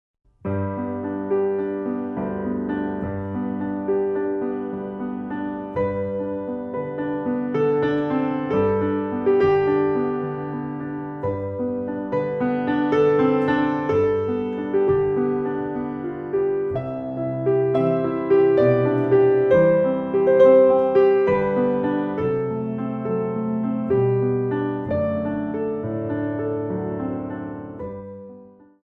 Piano music for the dance studio
Arrangements of Classical Compositions for Ballet Class